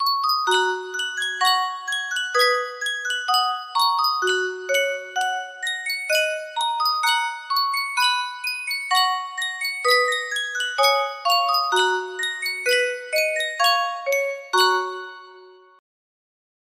Sankyo Custom Tune Music Box - Loch Lomond music box melody
Full range 60